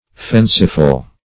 Fenceful \Fence"ful\, a. Affording defense; defensive.